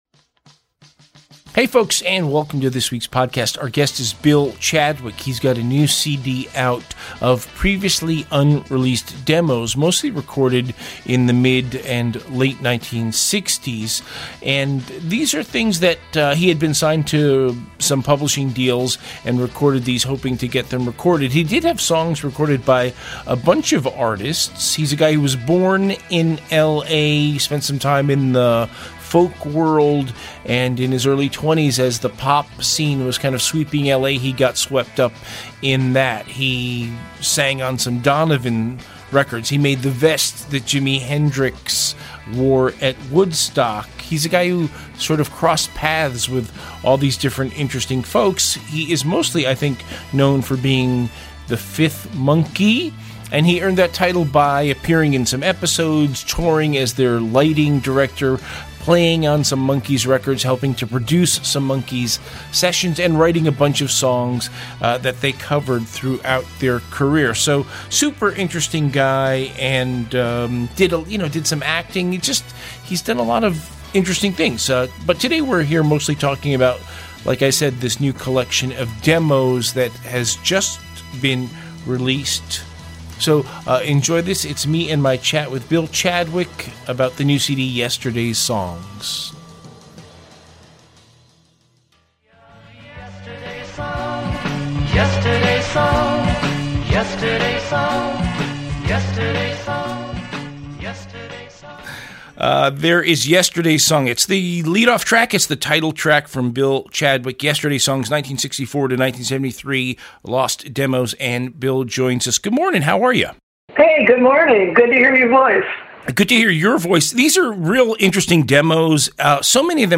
"Interview"https